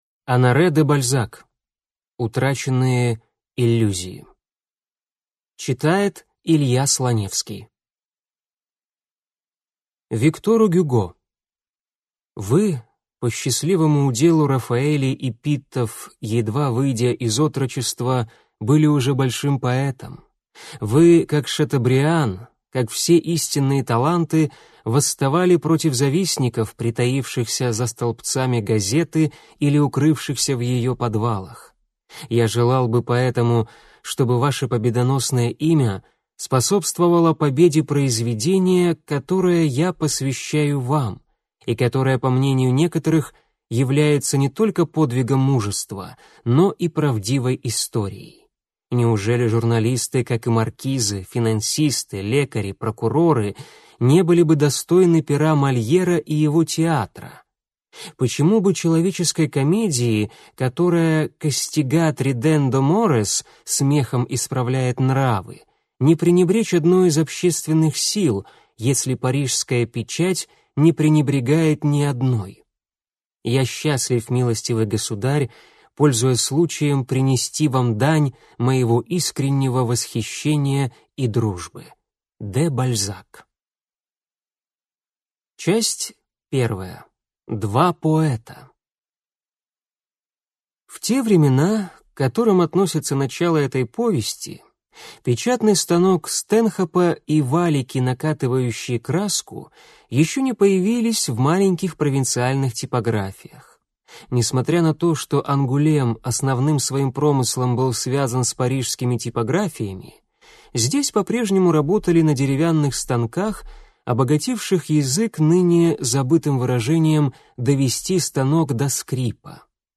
Аудиокнига Утраченные иллюзии | Библиотека аудиокниг